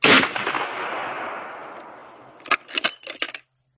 دانلود صدای تفنگ 4 از ساعد نیوز با لینک مستقیم و کیفیت بالا
جلوه های صوتی